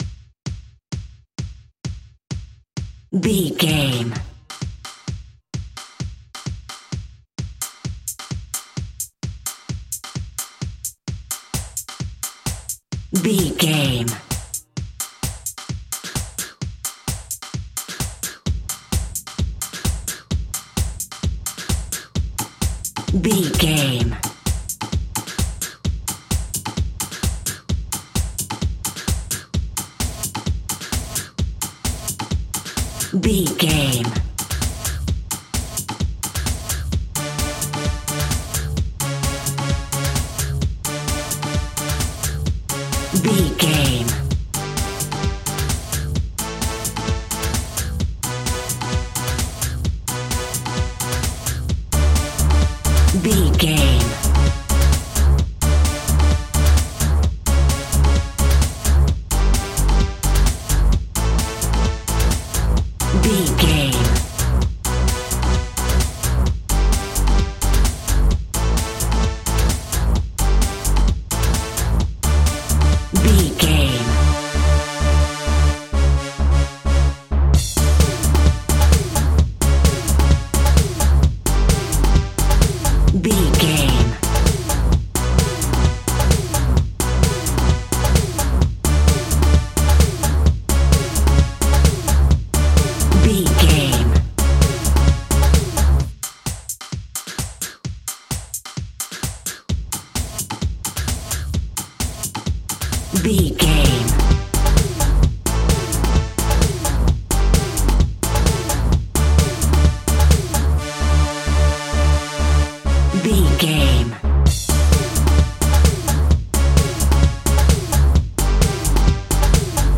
Dark House Music.
Aeolian/Minor
aggressive
groovy
smooth
futuristic
industrial
frantic
drum machine
synthesiser
electro house
dark house